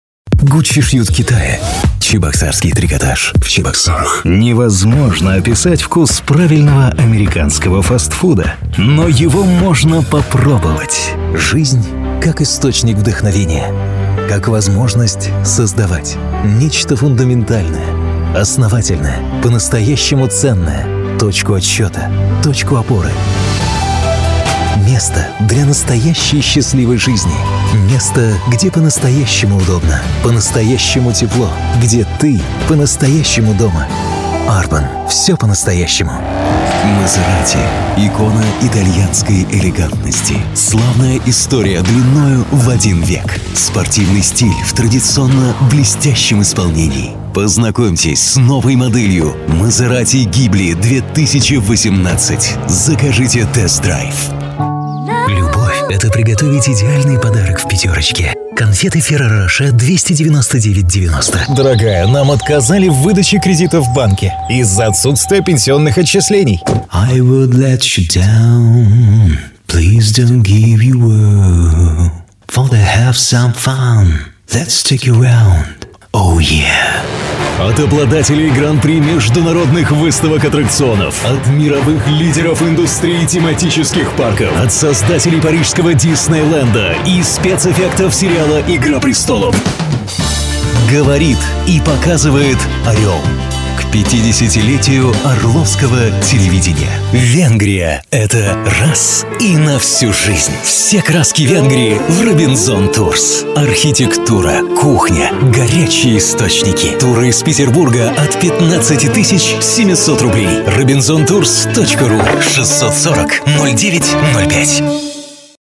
Бас-баритон, который может почти всё:)